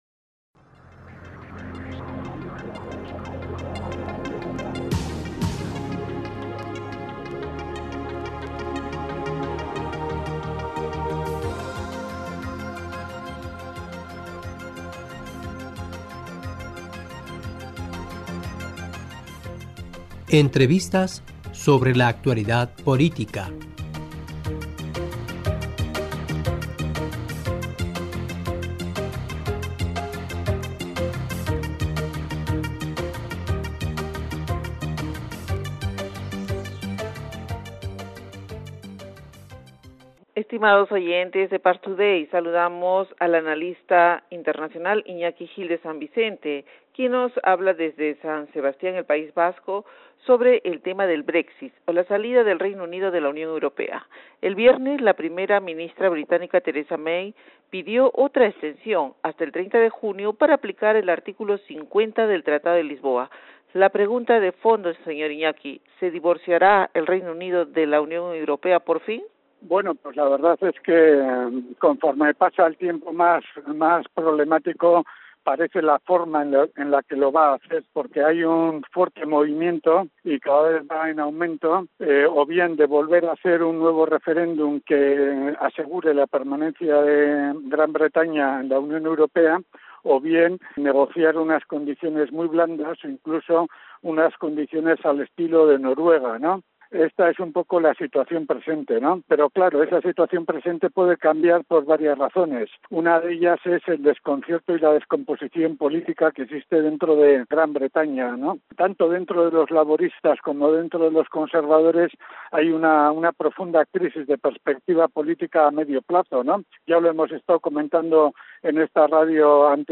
Parstoday- Entrevistadora (E)